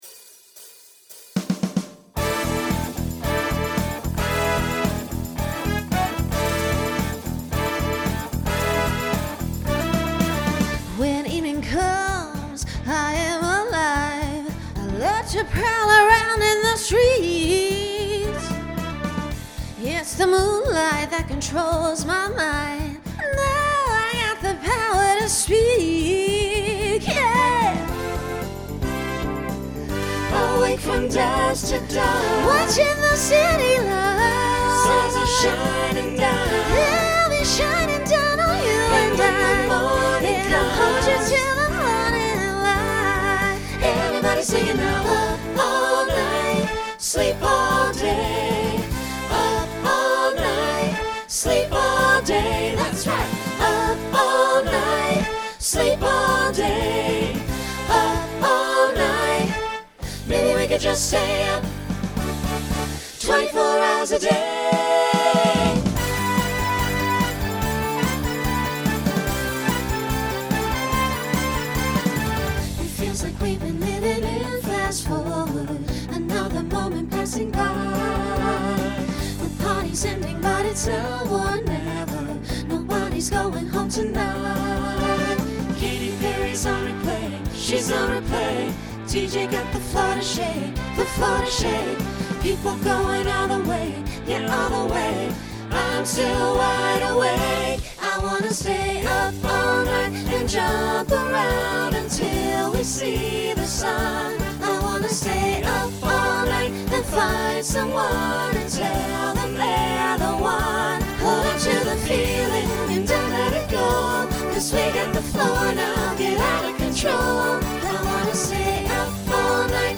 Pop/Dance , Rock
Voicing SATB